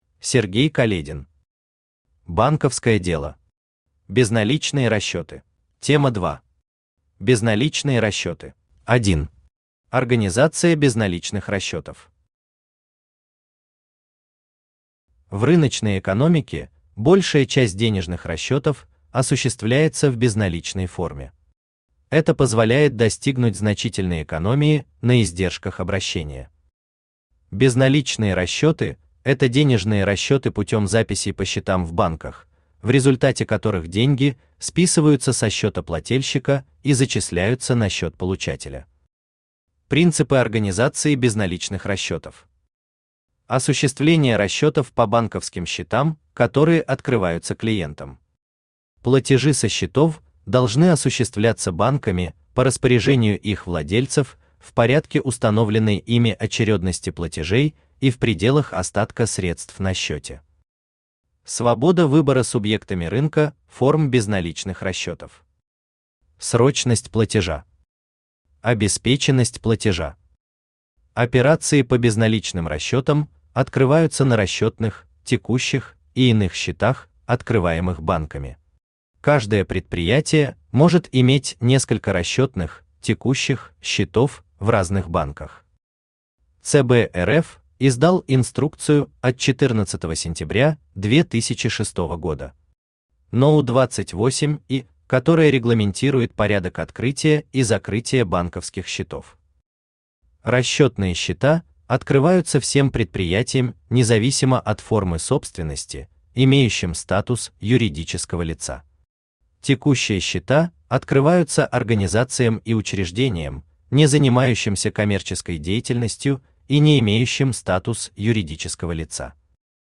Аудиокнига Банковское дело. Безналичные расчеты | Библиотека аудиокниг
Безналичные расчеты Автор Сергей Каледин Читает аудиокнигу Авточтец ЛитРес.